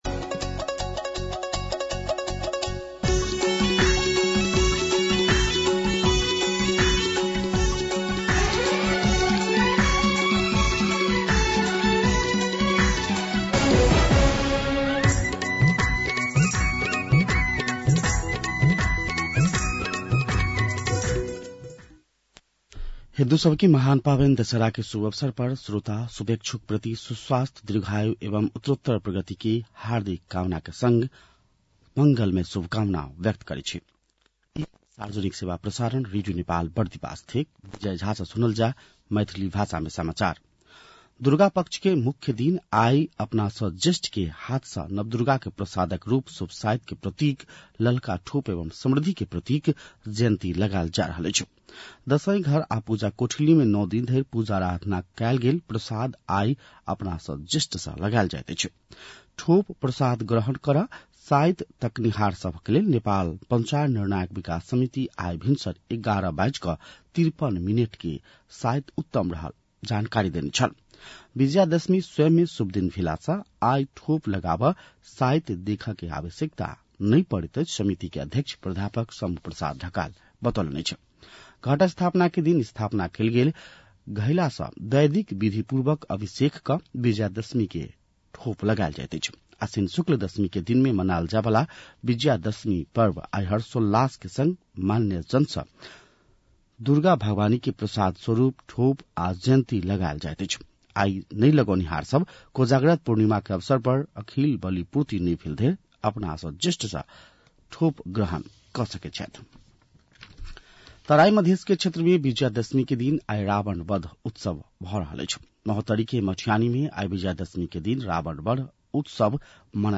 मैथिली भाषामा समाचार : १६ असोज , २०८२
6.-pm-maithali-news-.mp3